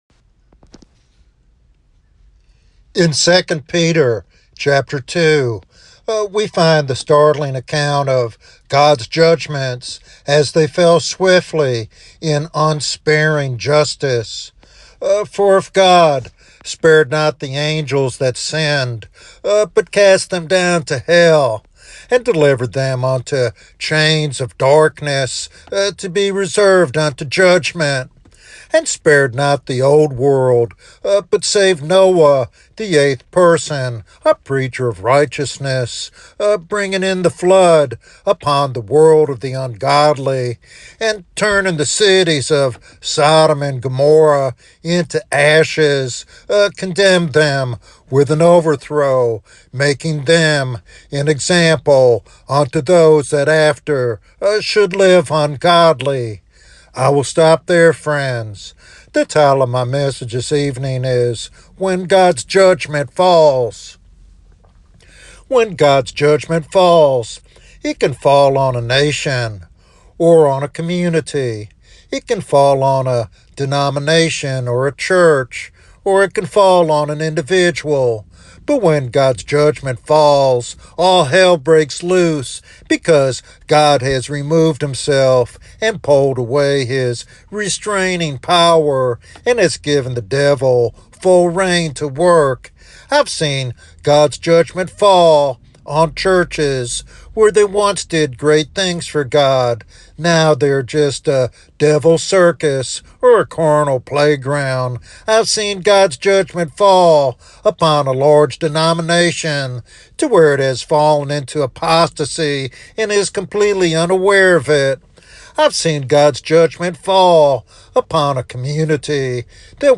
Yes, the sermon highlights examples where entire churches or denominations have fallen into apostasy and judgment.